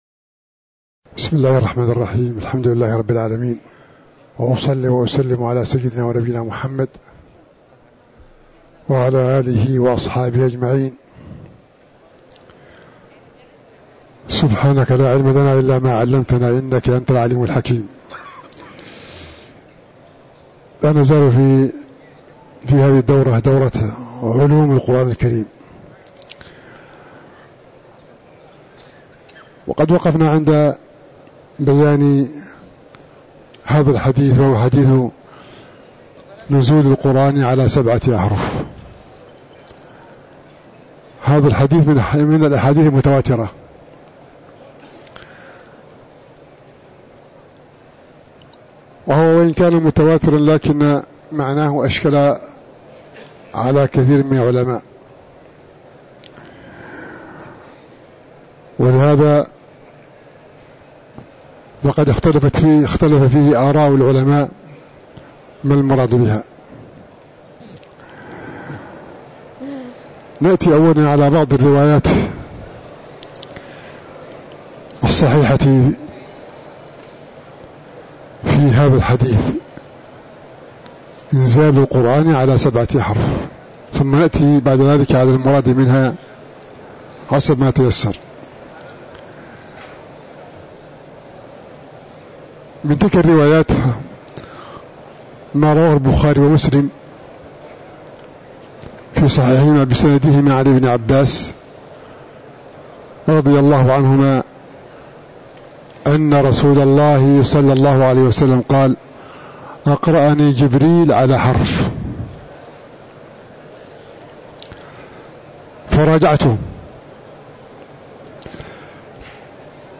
تاريخ النشر ٣ صفر ١٤٤٥ هـ المكان: المسجد النبوي الشيخ